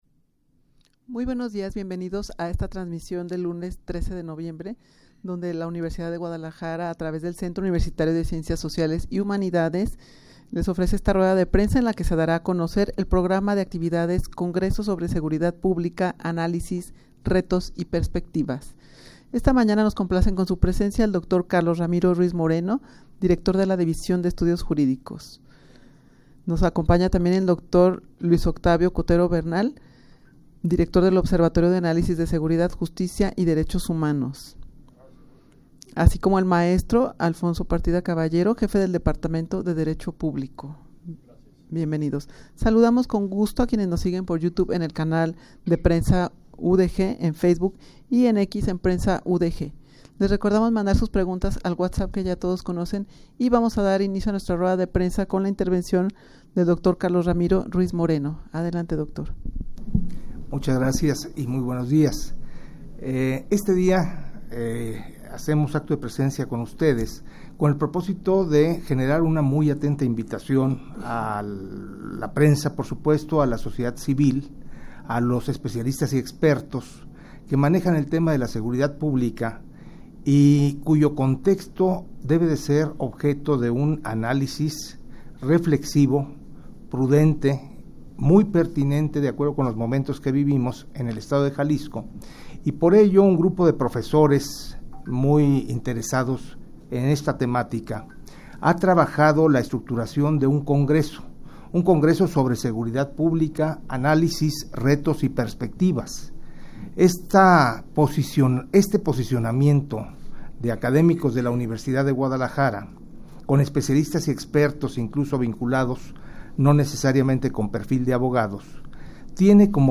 Audio de la Rueda de Prensa
rueda-de-prensa-en-la-que-se-dara-a-conocer-el-programa-de-actividades-congreso-sobre-seguridad-publica.mp3